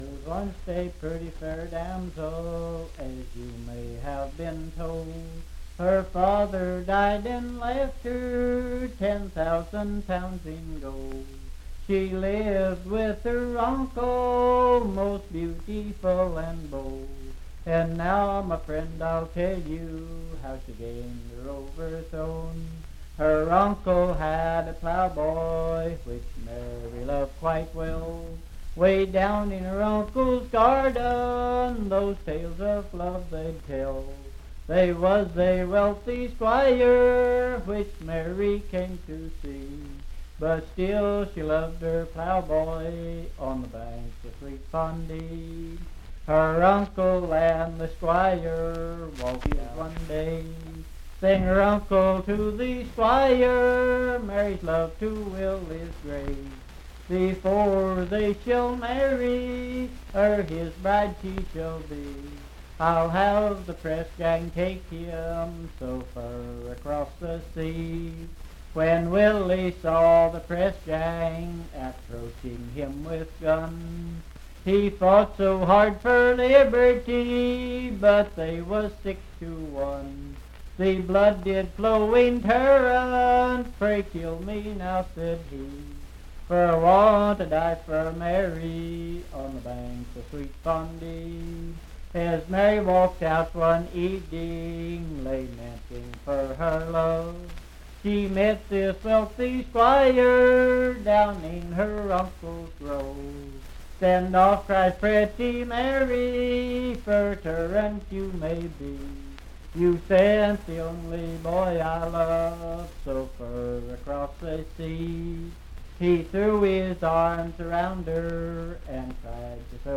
Unaccompanied vocal music
in Riverton, Pendleton County, WV.
Voice (sung)